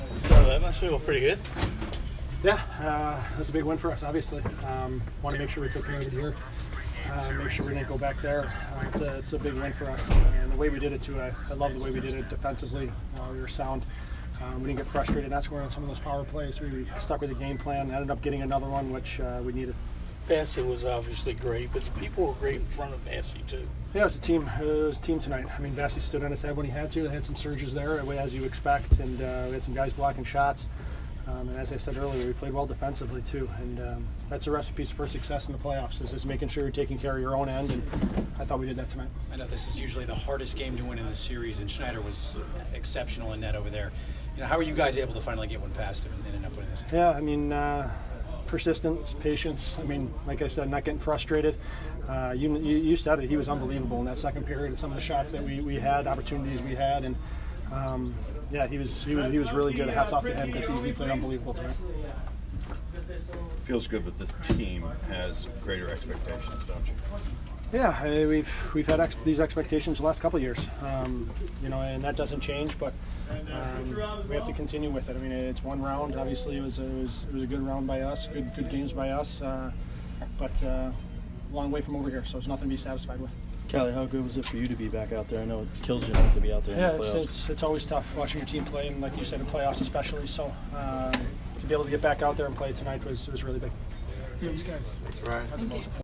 Ryan Callahan post-game 4/21